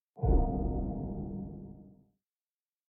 令牌道具获取.ogg